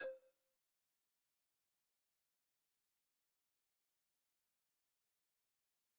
cursor_style_5.wav